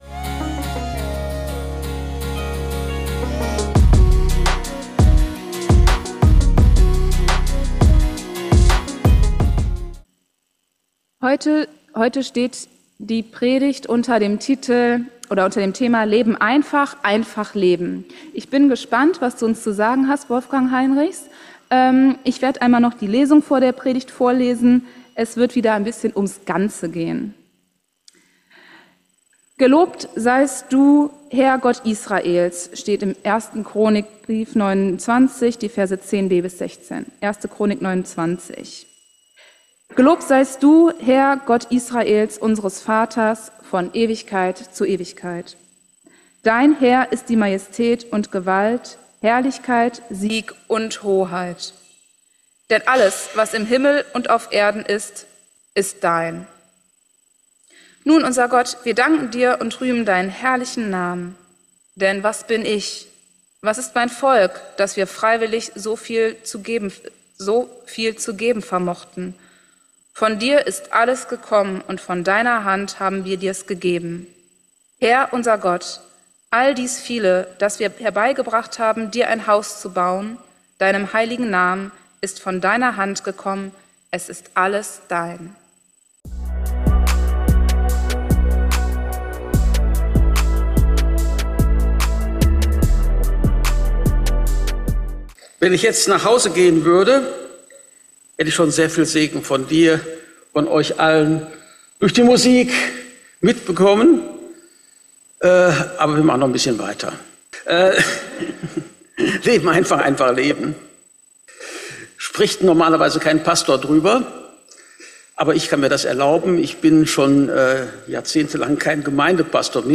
Eine Predigt auch über Gottvertrauen.